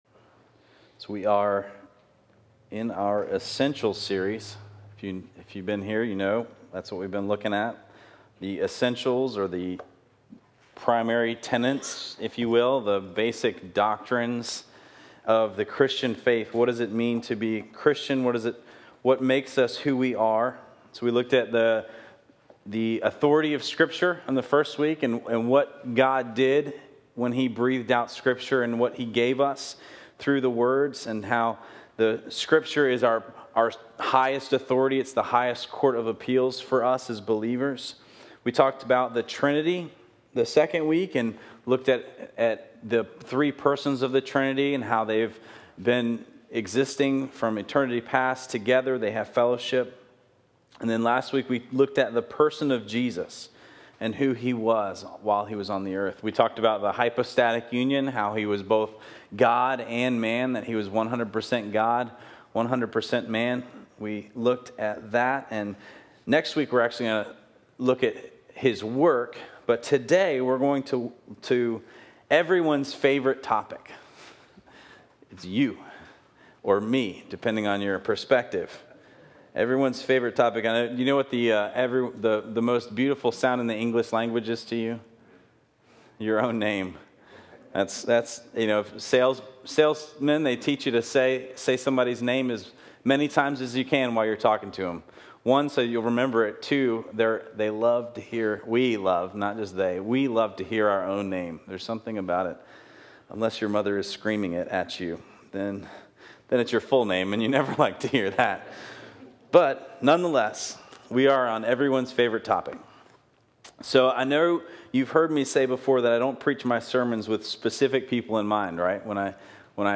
Today I do have specific people in mind while preparing and now preaching this sermon.